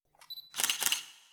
camera4.wav